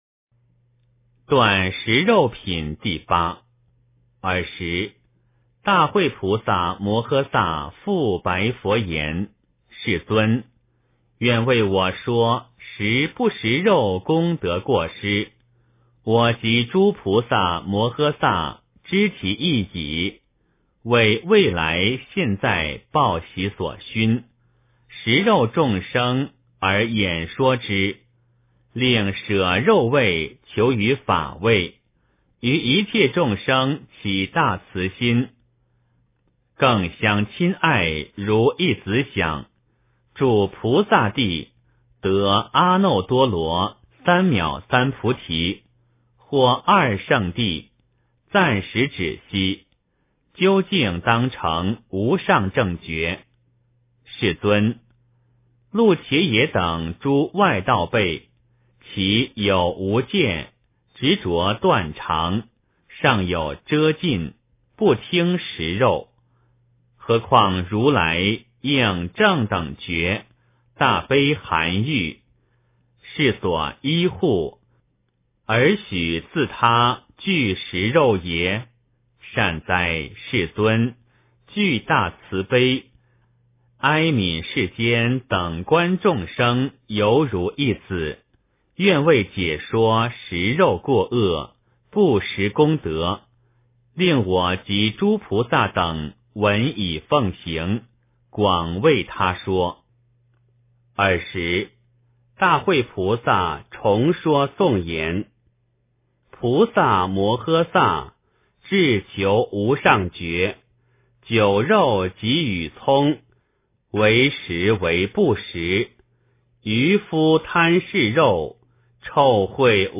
楞伽经（断食肉品第八） - 诵经 - 云佛论坛